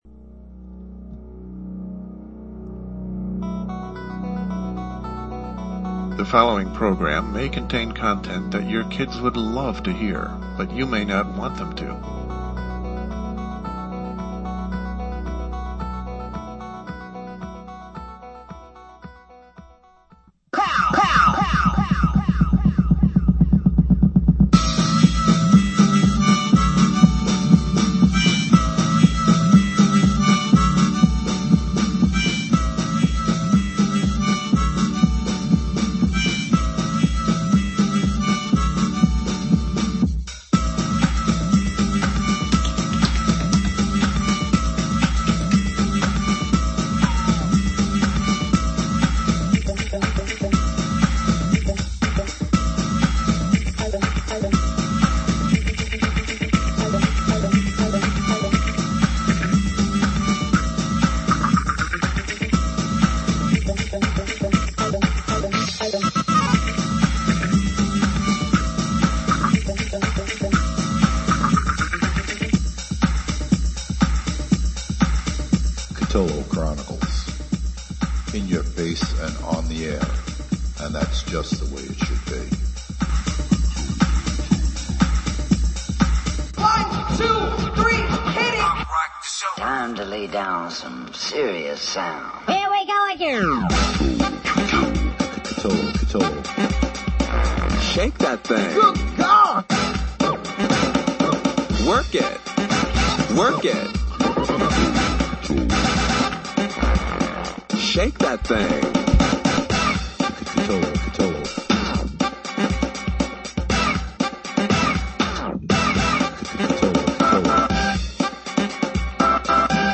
LIVE at 9:00 p.m. Eastern time